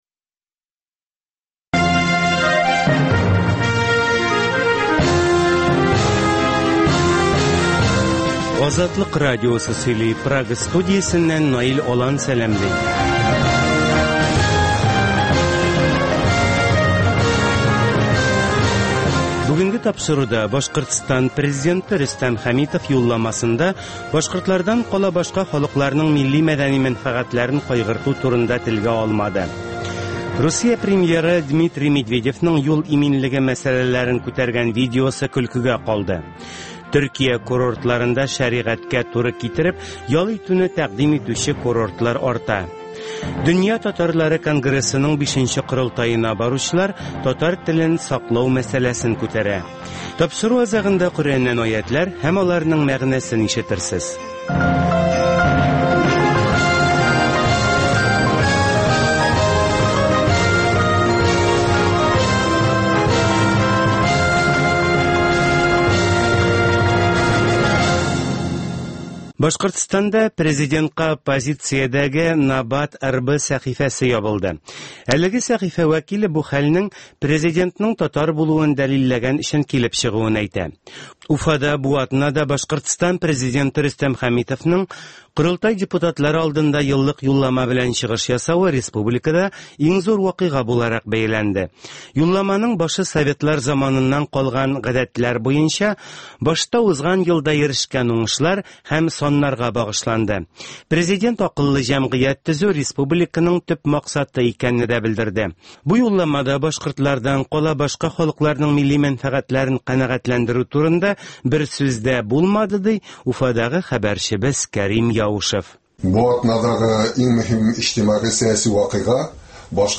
Азатлык узган атнага күз сала - Башкортстаннан атналык күзәтү - Татар дөньясы - Түгәрәк өстәл сөйләшүе - Коръәннән аятләр һәм аларның мәгънәсе